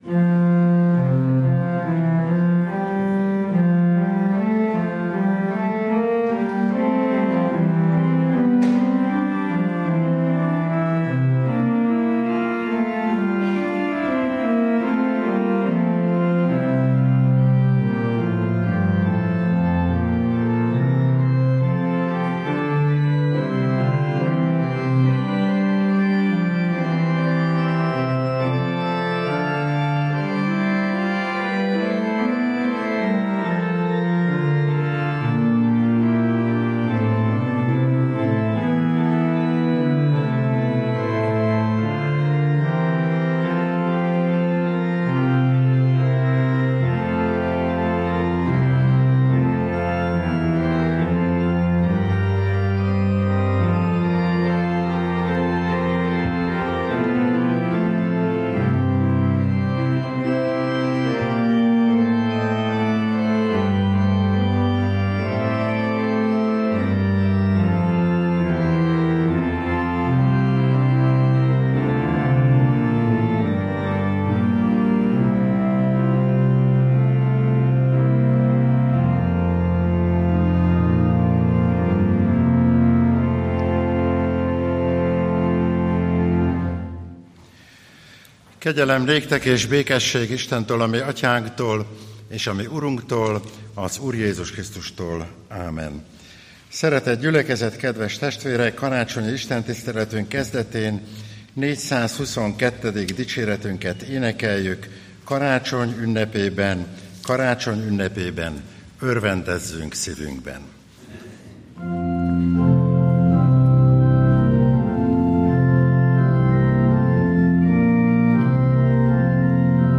Bogárdi Szabó István 2022. december 25. karácsony